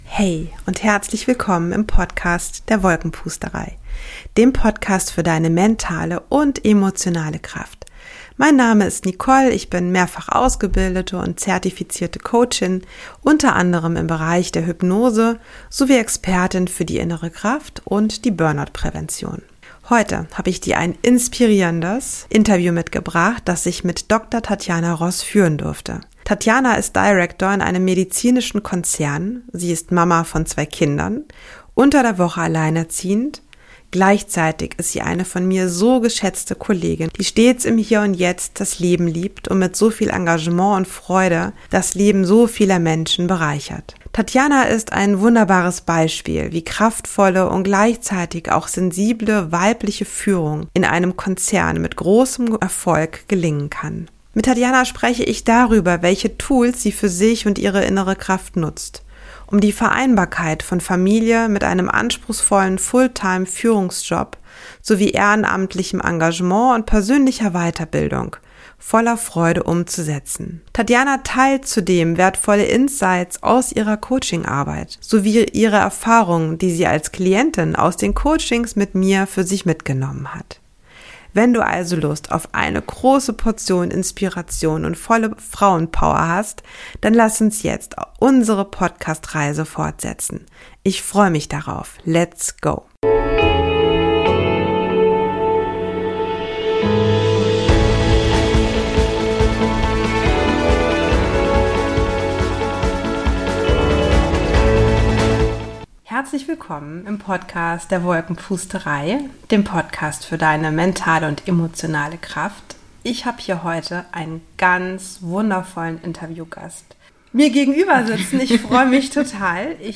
Beschreibung vor 1 Jahr In dieser Folge erwartet dich inspirierendes Interview mitgebracht